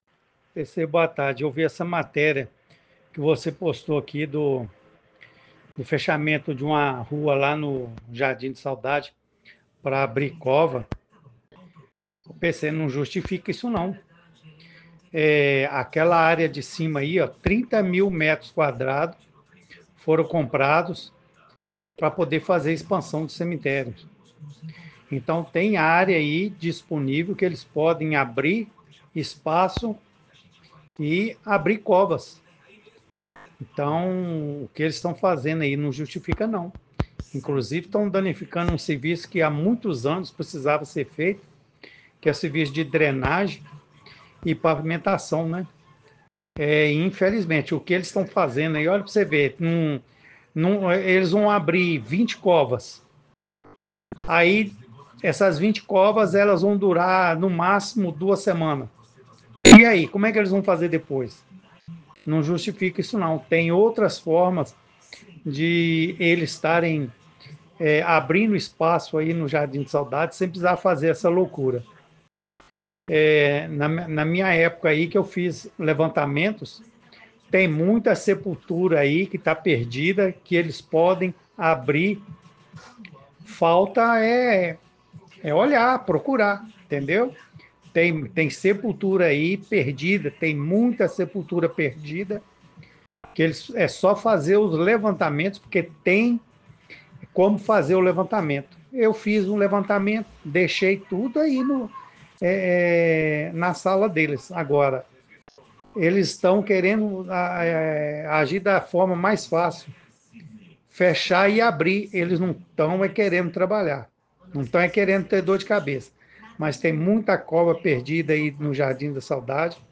A medida surpreendeu proprietários de jazigos. Ouça o importante depoimento de uma pessoa que trabalhou no redimencionamento do Cemitério.